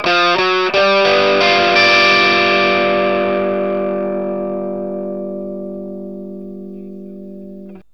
PICK1 G 7 60.wav